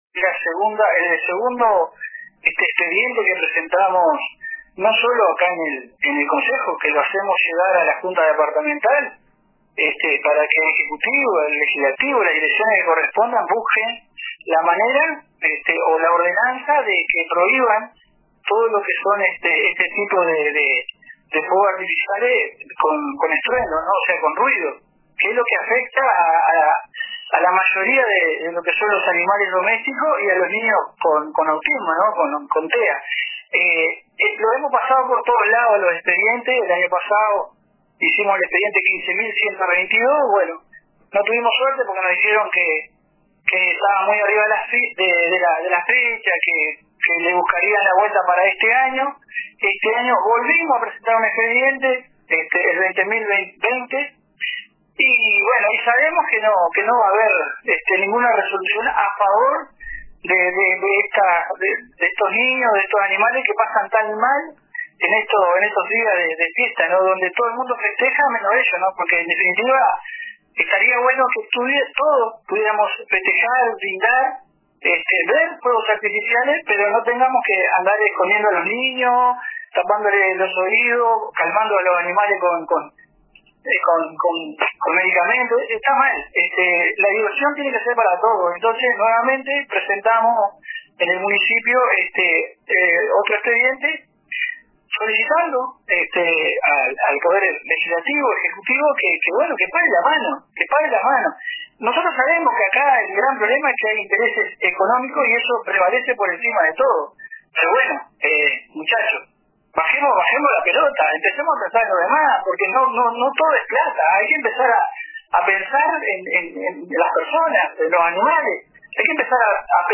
“Que paren la mano” dijo a RADIO RBC el Concejal del Municipio de Piriápolis por el Frente Amplio, Alberto Miranda